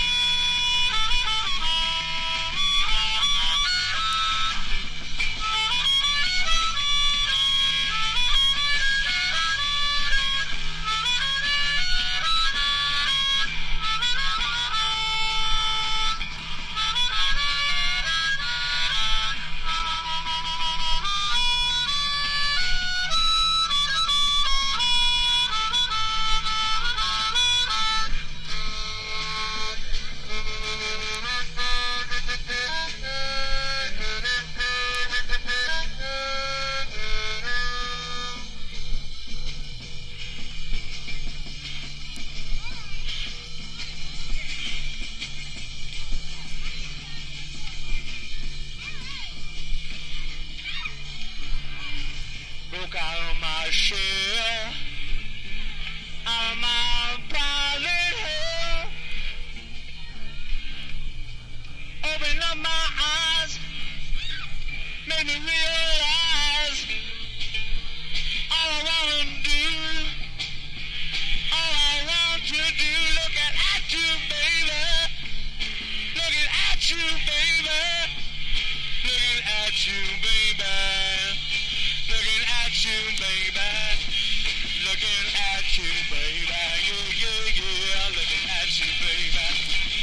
とにかくうるさいのに上手いです！
初期PUNK / POWER POP